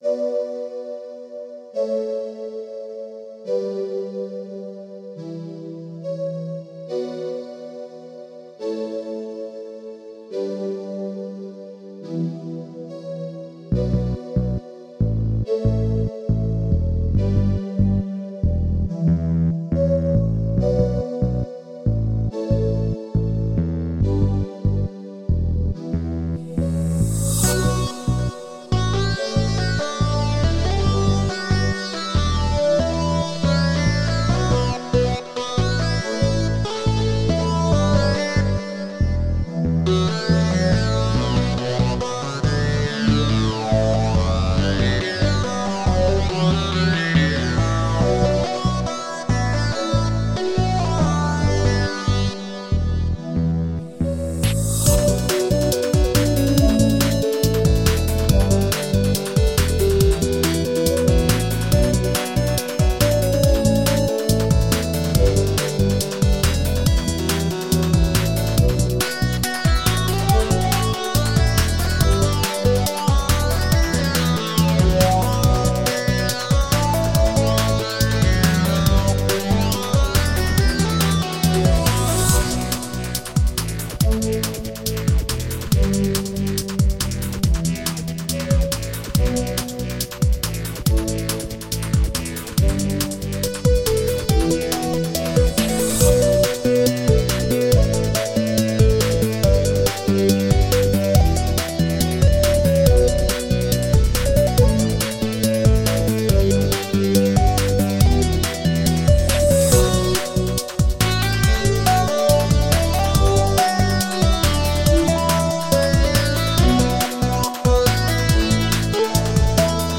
I suoni sono particolari e prodotti, così come li sentiamo, dal gusto dell’autore senza il ricorso a suoni preconfezionati da altri.